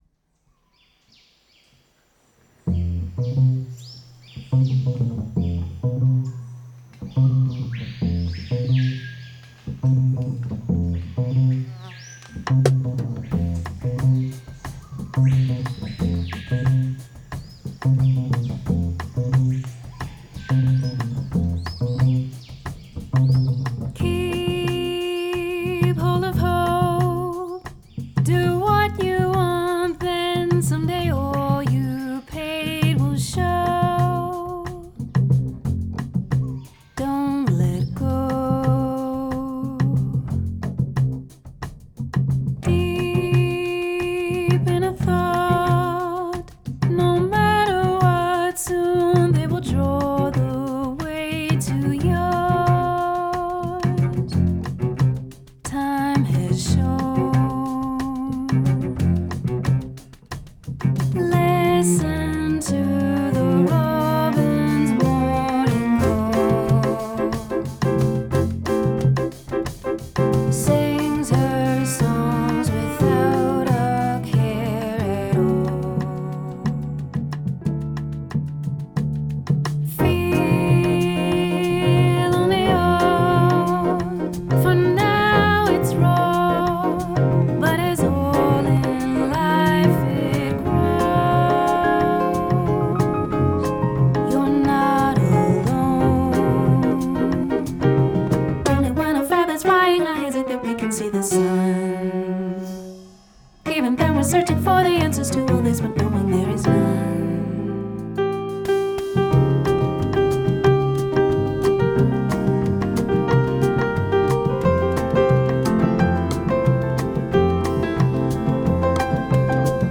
Cello
Guitar
Piano
Bass
Percussion
Drums